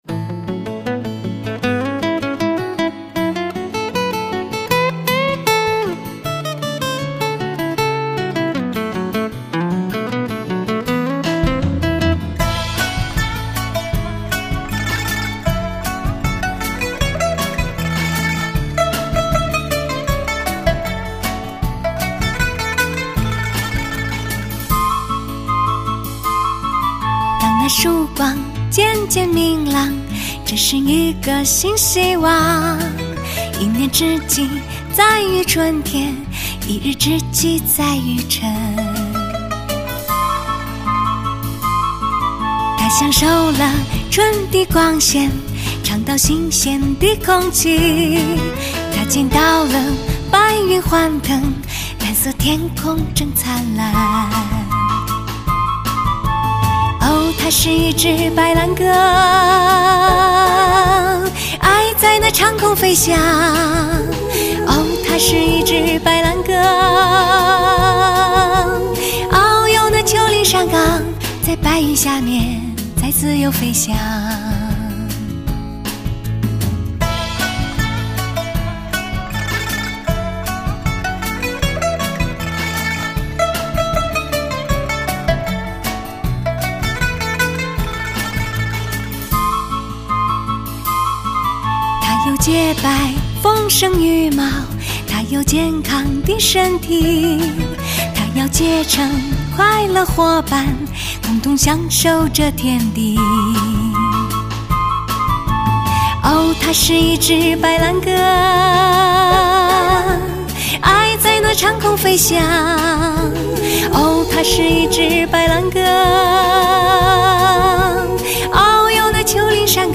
“民乐编配手法大胆尝试”：中国民乐与世界音乐的一次伟大融合，
向世界展示中国民族音乐的独特魅力；二胡、琵琶、古筝、竹笛、
葫芦丝、箫……浓郁的民族特色，经典的外国歌曲